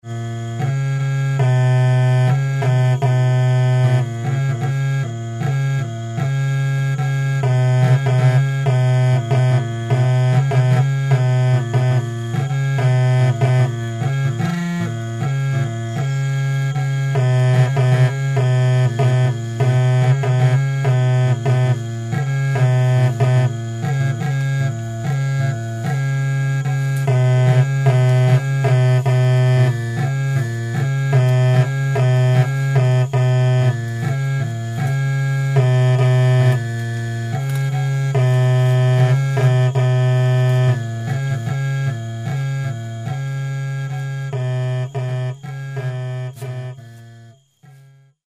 Outro instrumento muito usado na aldeia é um trio de sopros com palheta chamado tuturap: